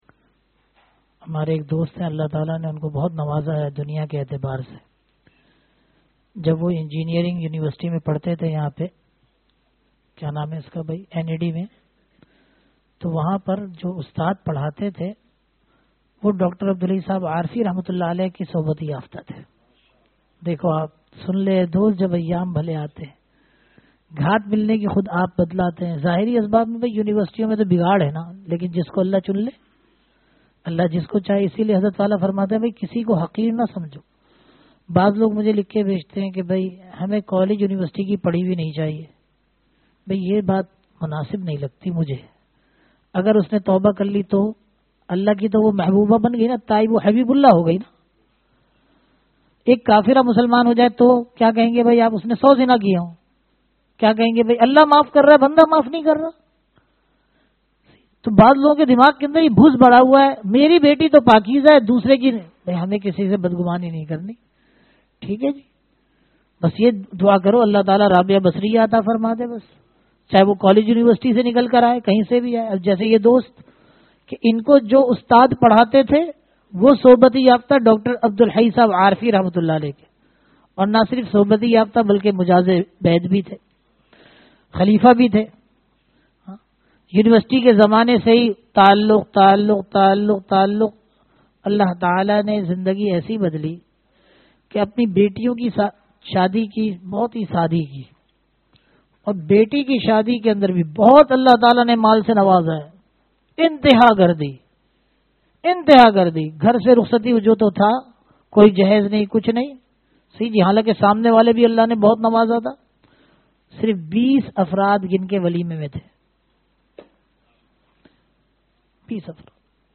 Bayanat
(bad magrib)